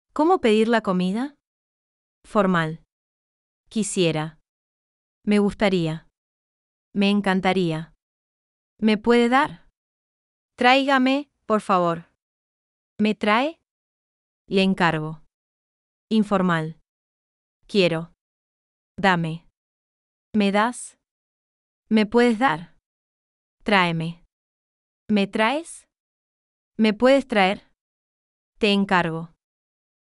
Press the audio to hear how these expressions are pronounced.